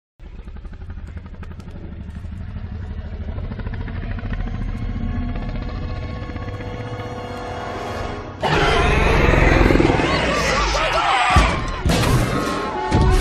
indominus rex roar jurassic world Meme Sound Effect
indominus rex roar jurassic world.mp3